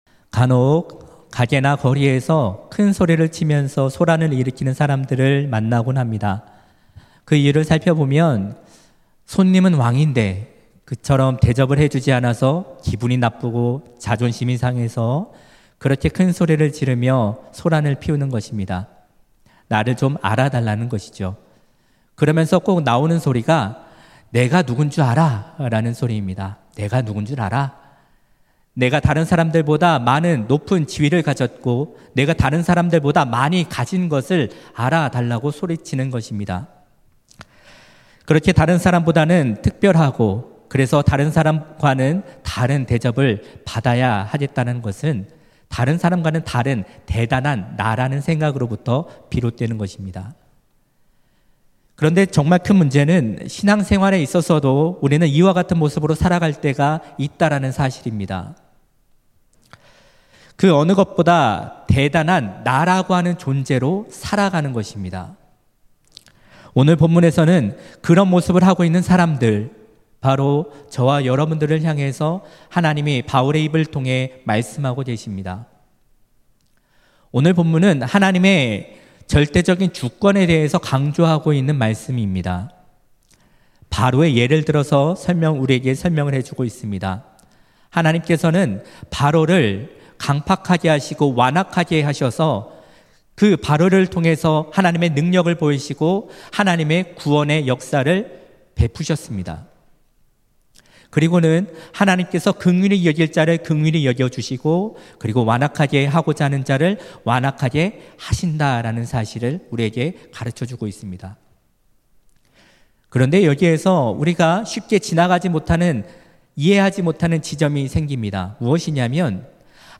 2024년 8월 2일 금요성령기도회